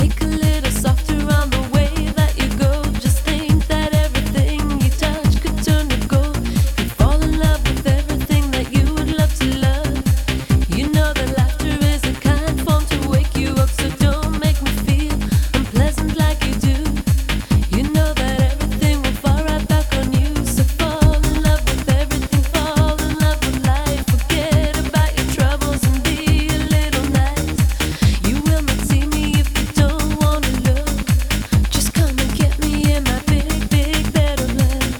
Trance Dance
Жанр: Танцевальные / Транс